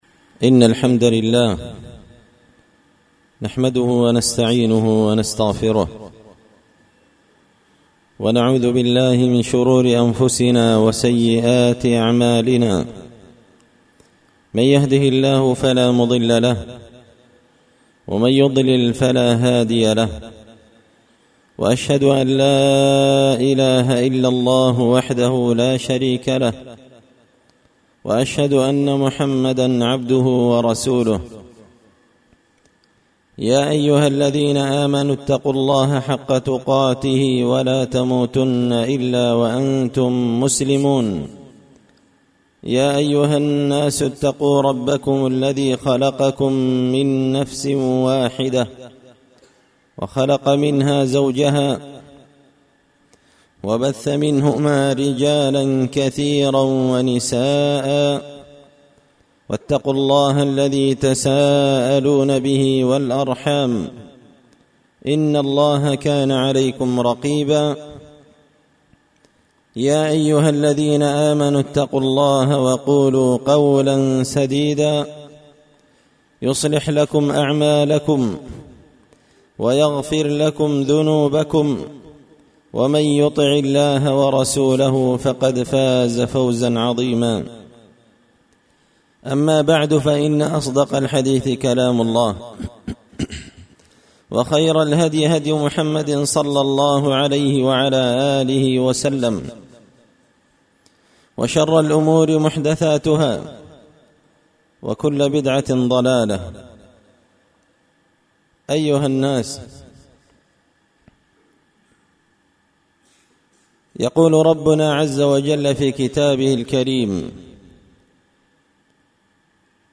خطبة في عشر ذي الحجة 1441
دار الحديث بمسجد الفرقان ـ قشن ـ المهرة ـ اليمن
خطبة-في-عشر-ذي-الحجة-1441ـــــــ.mp3